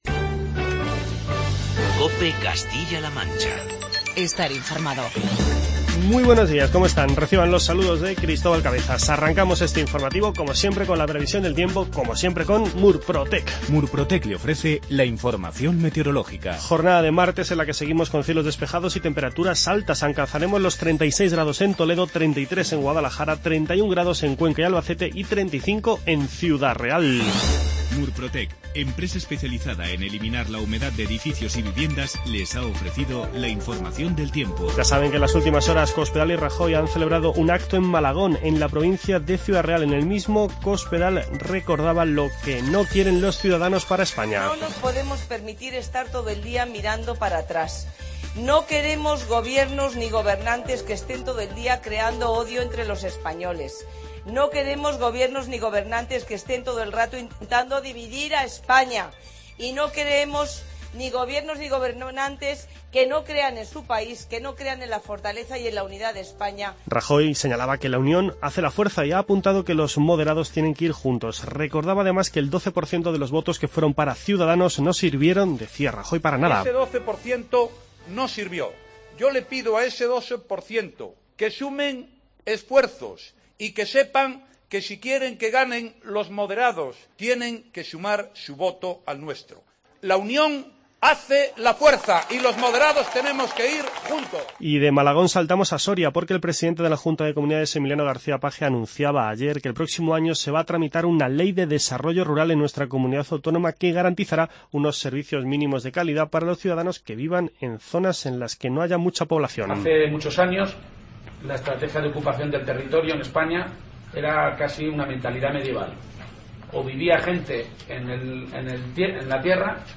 Informativo regional
Destacamos, entre otras, las palabras de Mariano Rajoy, María Dolores Cospedal y Emiliano García-Page.